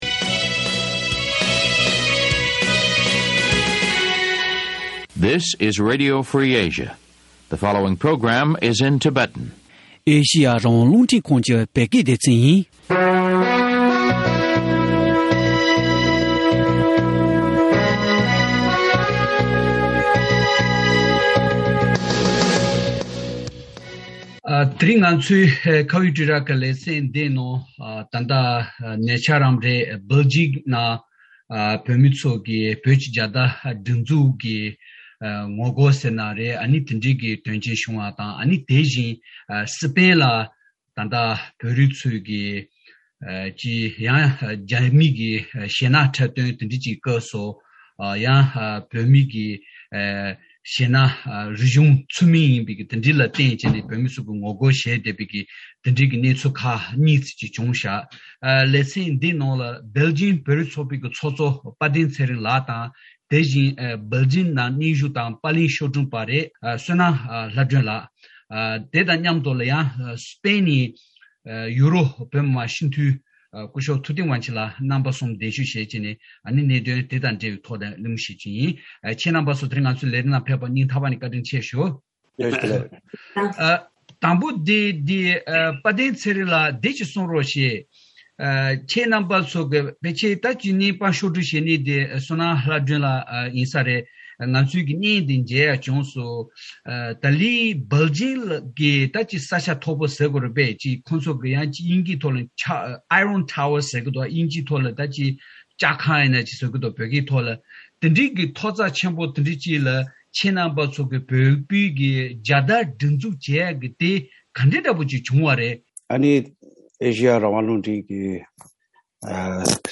བཀའ་མོལ་ཞུས་པ།